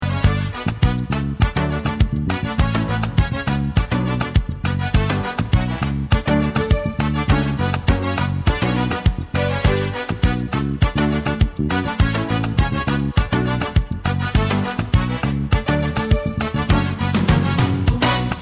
Le style : Zouk Love Antillais aux influences Reggae, Ragga